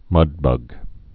(mŭdbŭg)